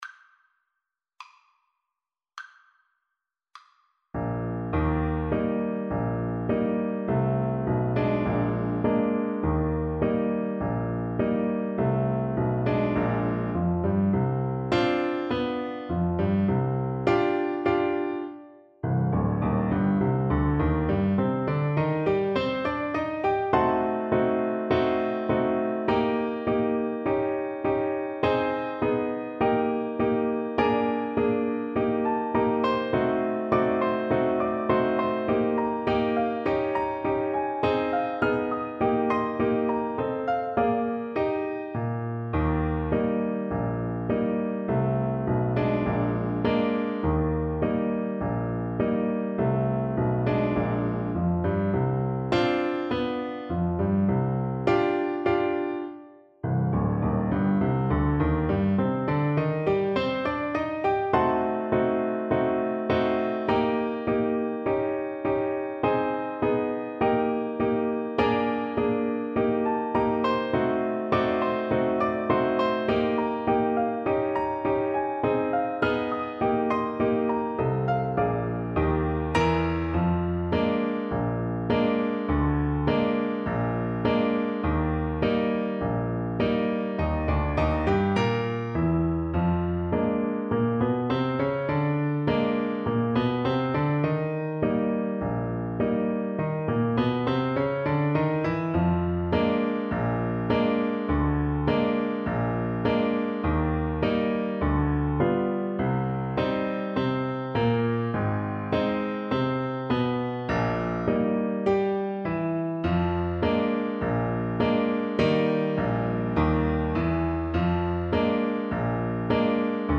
2/4 (View more 2/4 Music)
Slow march tempo. = 74 Slow march tempo
Ragtime Music for Trombone